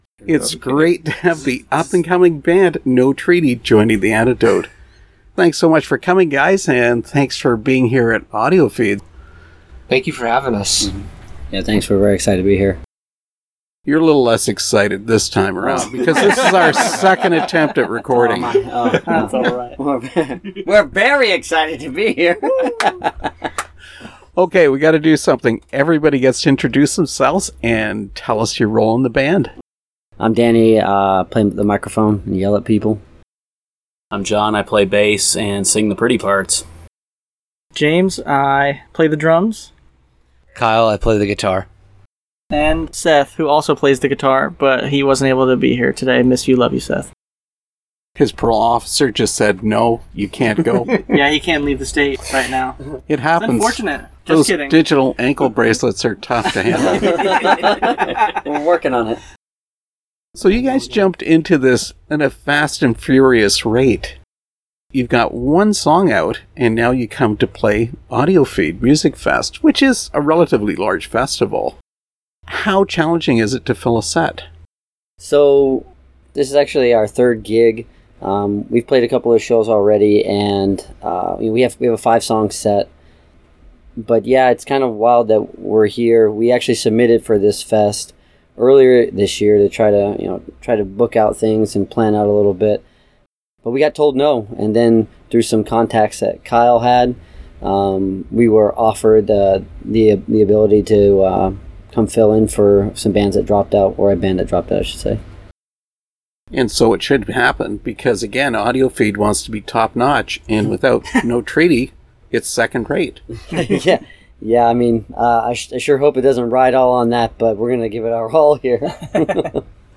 Interview with No Treaty